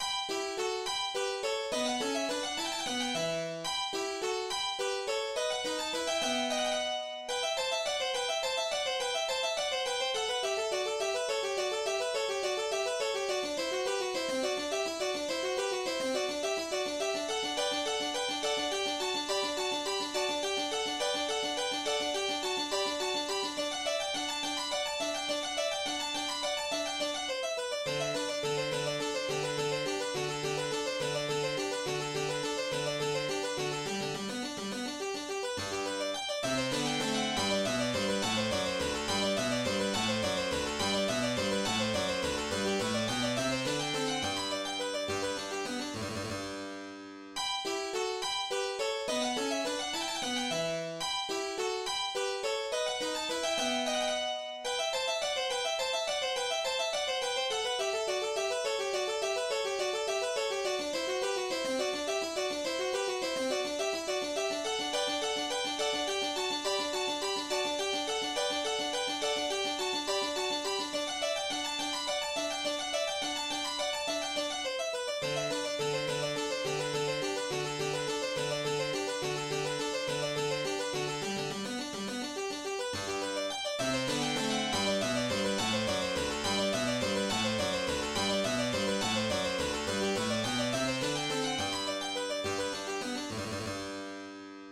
mi mineur
La sonate K. 15, en mi mineur, est notée Allegro.
Première section de la sonate en mi mineur K. 15, de Domenico Scarlatti.